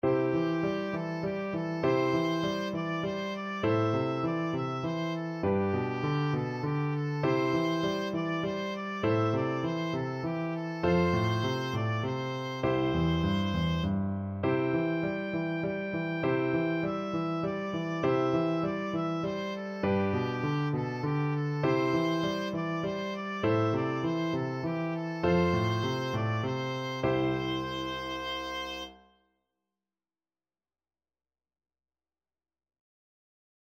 G5-G6
C major (Sounding Pitch) (View more C major Music for Oboe )
3/4 (View more 3/4 Music)